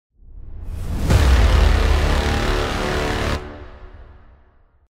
На этой странице собраны звуки внезапного появления: резкие переходы, неожиданные всплески, тревожные сигналы.
Эпическое появление